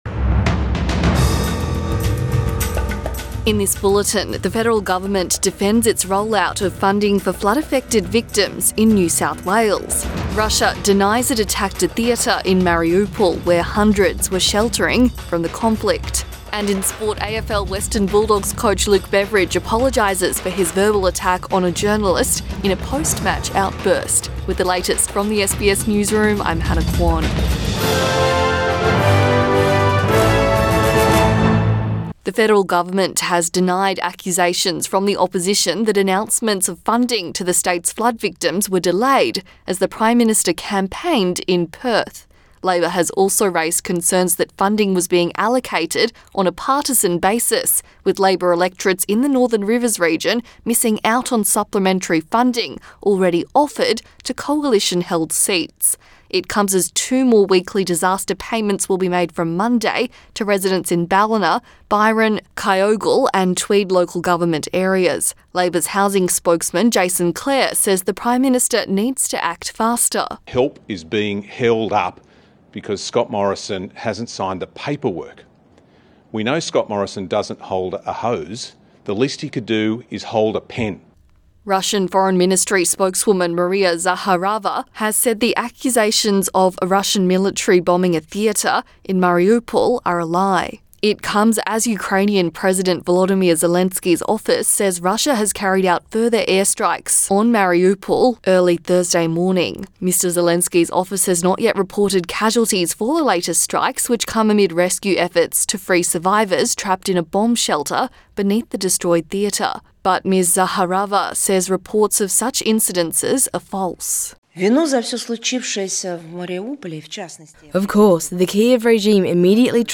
AM bulletin 18 March 2022